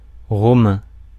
Ääntäminen
Synonyymit pigeon géant français vaticane Ääntäminen France Tuntematon aksentti: IPA: /ʁɔ.mɛ̃/ Haettu sana löytyi näillä lähdekielillä: ranska Käännös 1. rooma Suku: m .